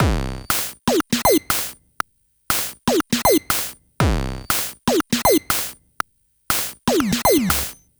120_BPM
ChipShop_120_Drums_07.wav